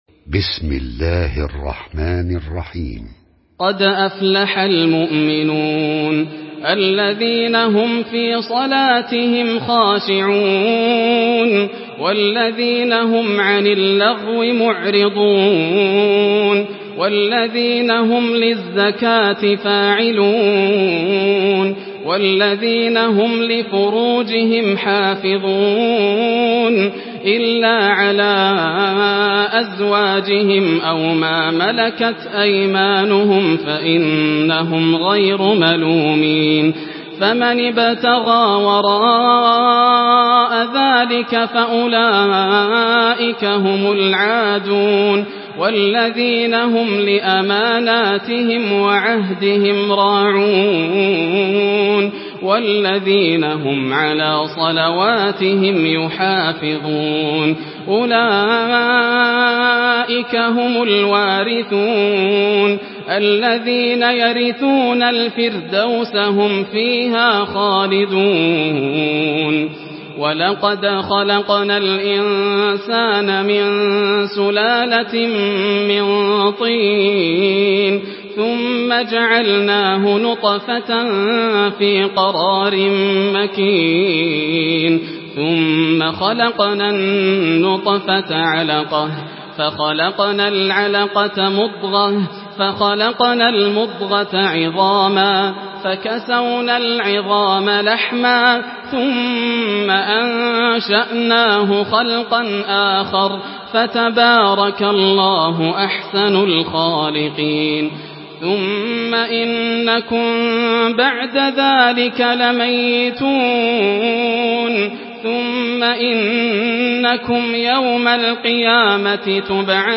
Surah Al-Muminun MP3 by Yasser Al Dosari in Hafs An Asim narration.